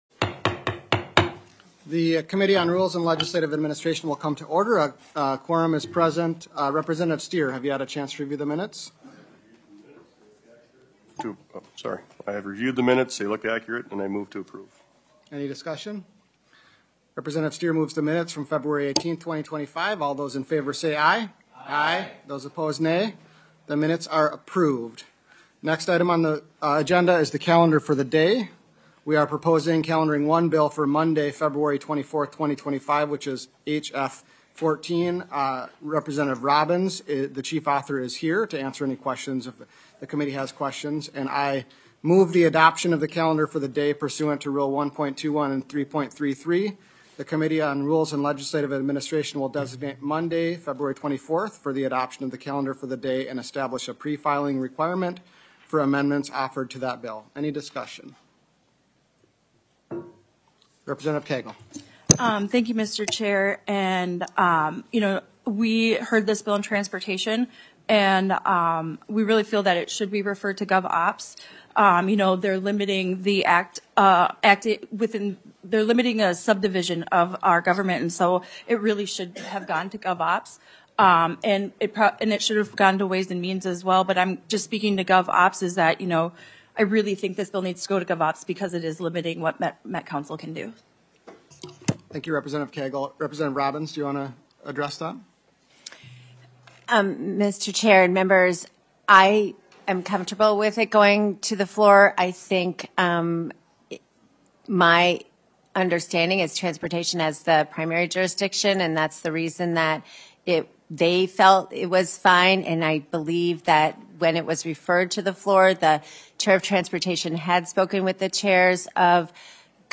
Majority Leader Niska, Chair of the Rules and Legislative Administration Committee, called the meeting to order at 10:03 A.M. on February 20th, 2025, in Room G23 of the State Capitol.